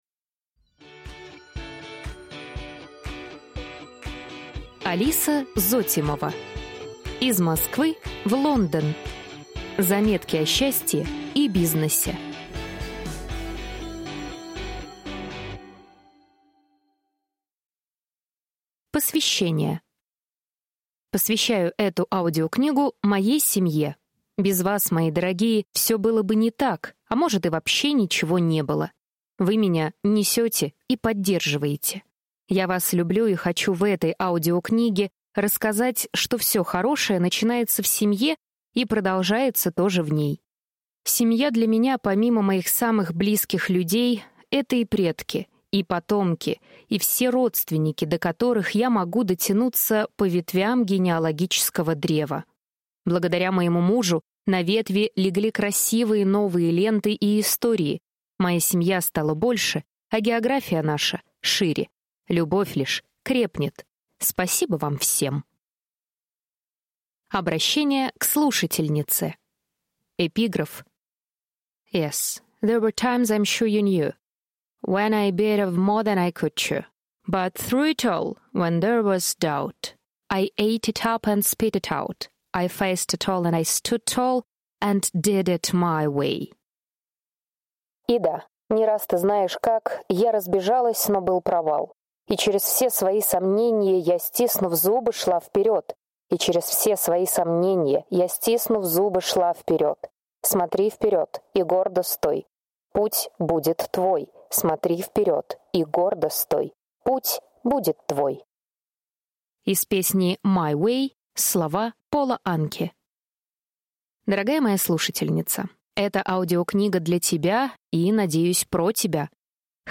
Аудиокнига Из Москвы в Лондон. Заметки о счастье и бизнесе | Библиотека аудиокниг
Прослушать и бесплатно скачать фрагмент аудиокниги